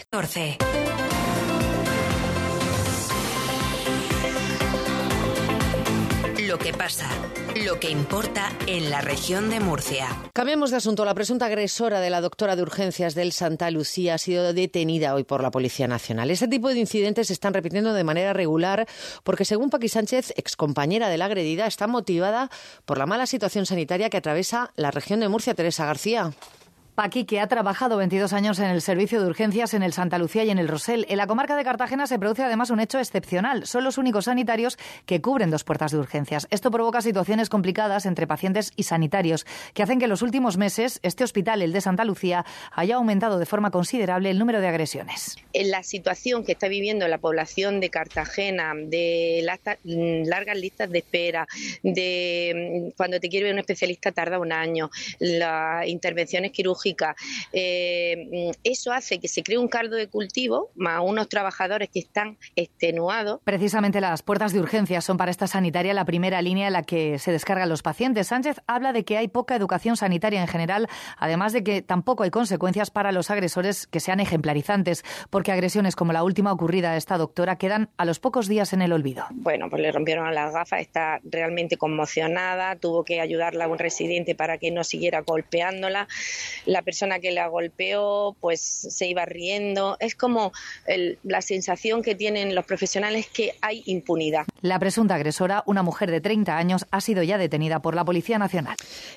Escucha aquí su intervención en el informativo Hoy por Hoy de Cadena SER Región de Murcia del 04/04/2025